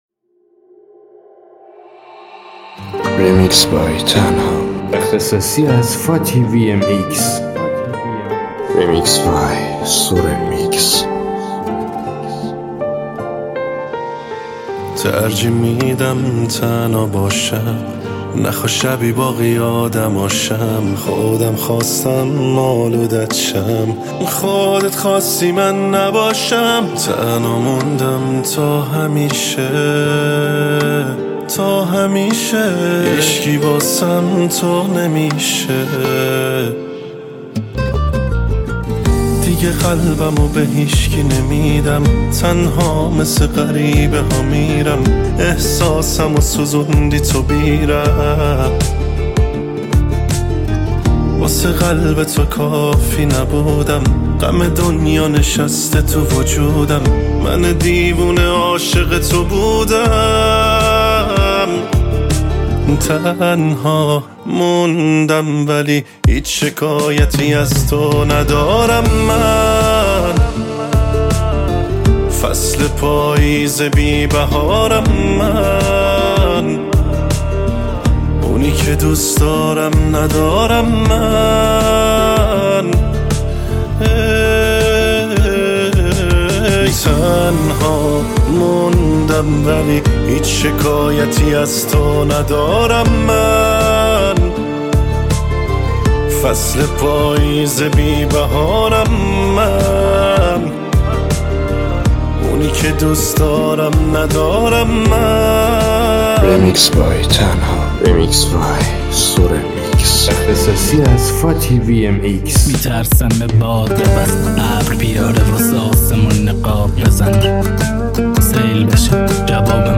ریمیکس رپ جدید